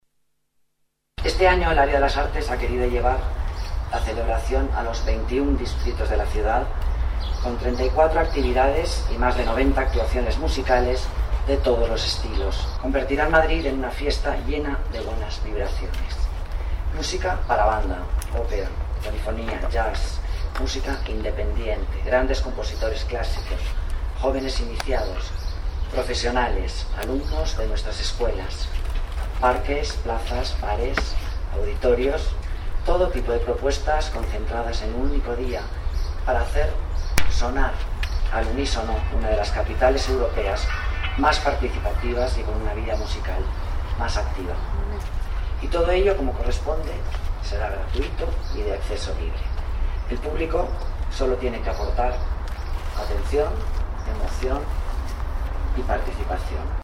Nueva ventana:Declaraciones de la delegada de Las Artes, Alicia Moreno: Día de la Música